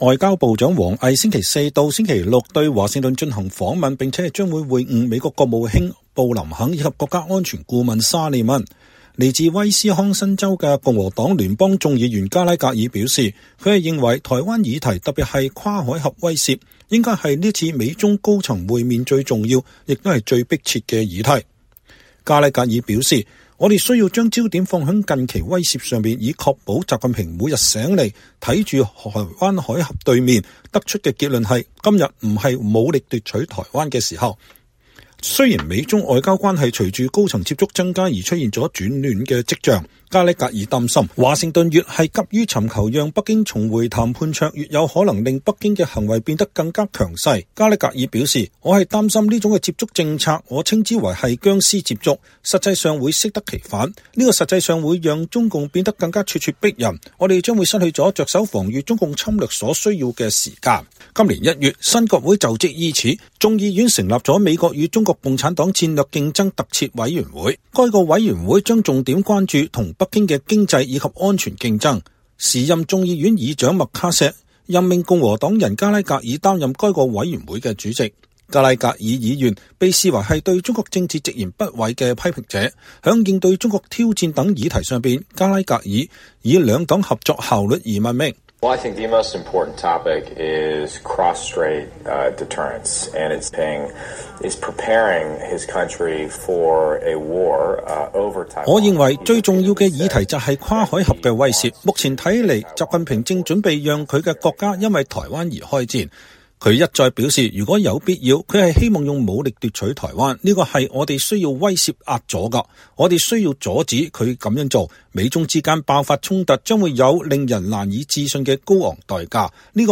專訪眾院美國與中共競爭委員會主席：抗衡中國 美國必須成為民主與威懾的軍火庫
美國國會眾議院美國與中共戰略競爭特設委員會主席麥克·加拉格爾眾議員2023年10月25日接受美國之音的專訪。